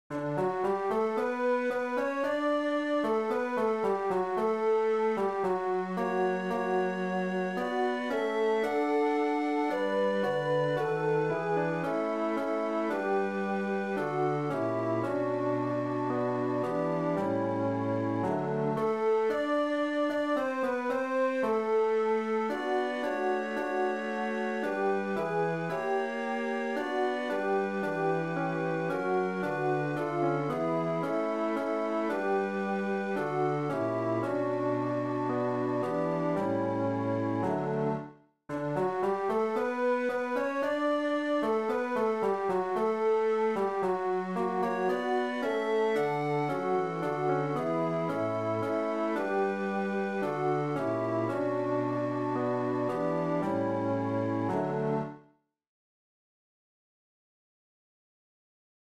Chorproben MIDI-Files 510 midi files